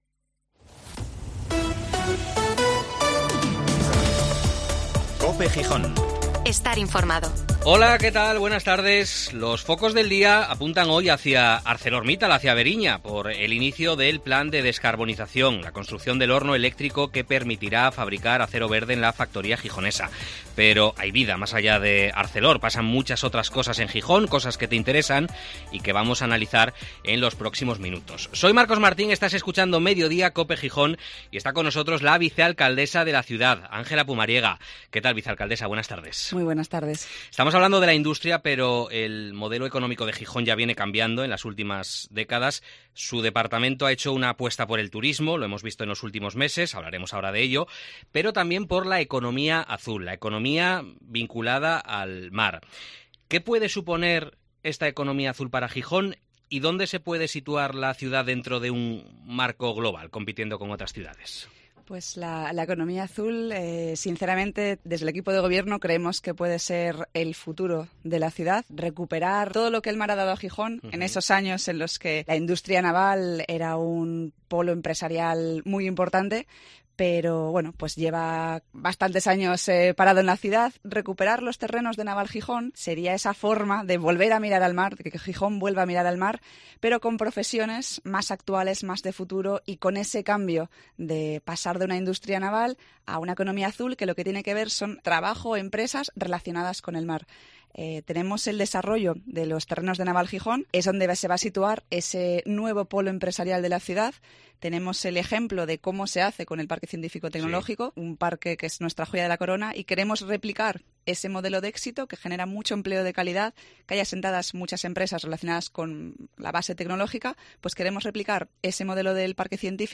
Entrevista a la vicealcaldesa de Gijón, Ángela Pumariega